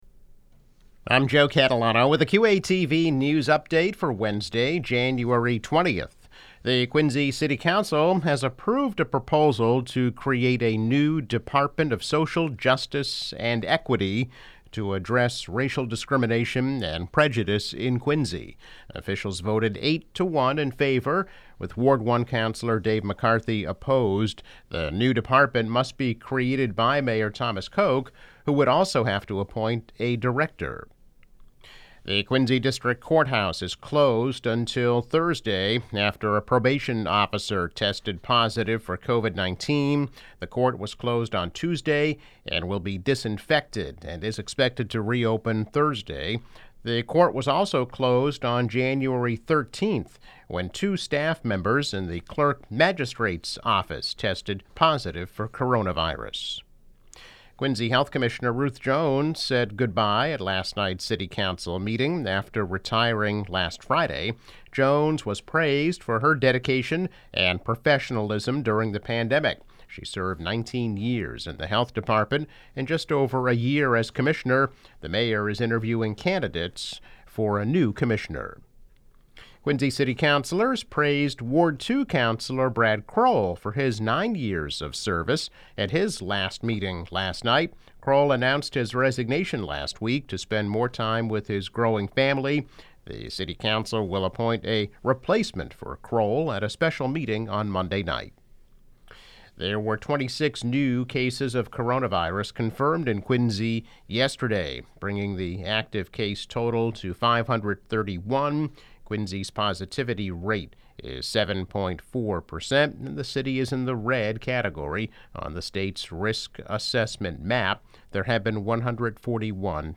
News Update - January 20, 2021